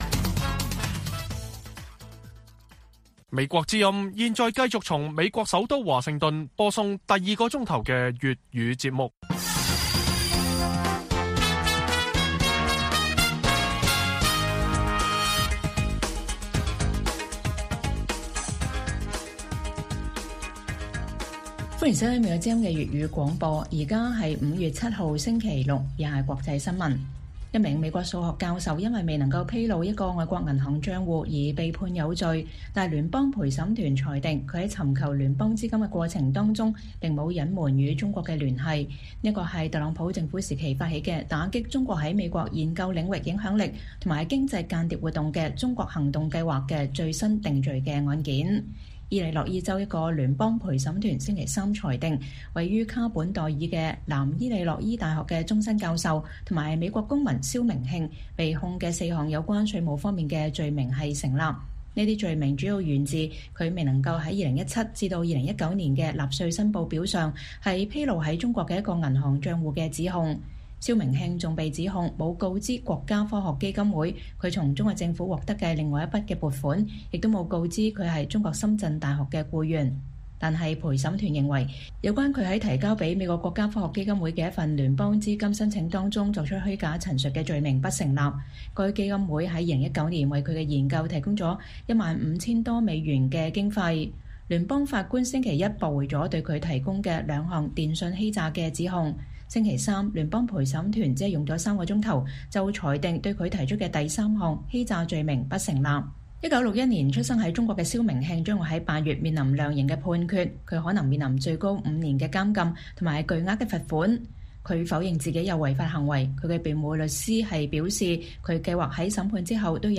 粵語新聞 晚上10-11點: 伊利諾伊一教授因未披露在中國的銀行賬號而被判有罪